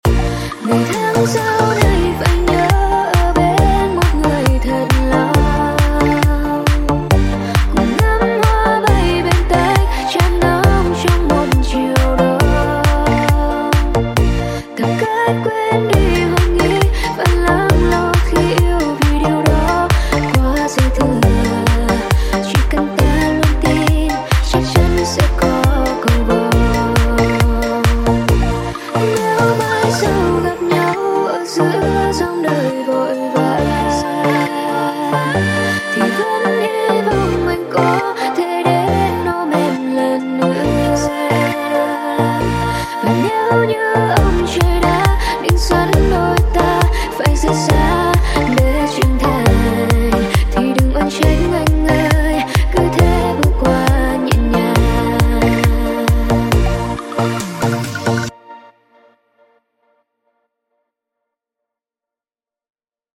Music
nghe nhẹ nhàng ha🥰
nghe nhẹ nhàng he